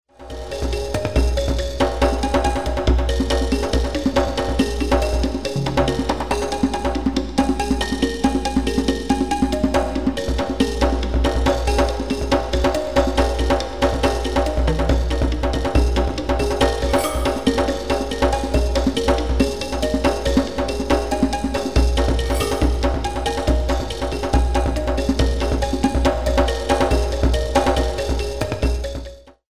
13 InstrumentalCompositions expressing various moods.
Percussion (driving beat)